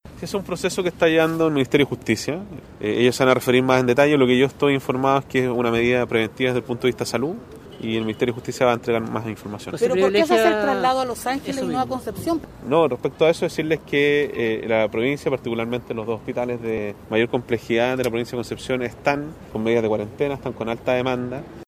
Ante los cuestionamientos respecto al lugar del traslado, el intendente Sergio Giacaman señaló que se trata de un proceso que le compete al Ministerio de Justicia.